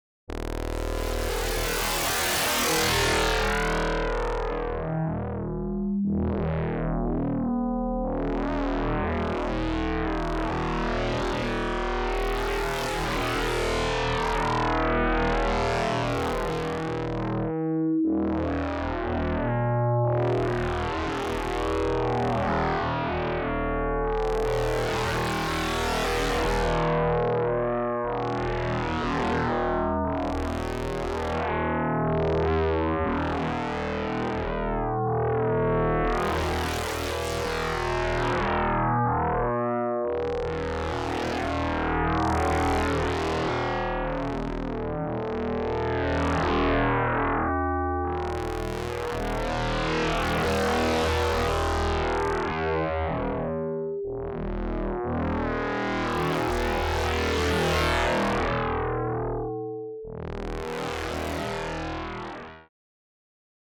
Simple case of concatenative frequency (phase) modulation (p. 273)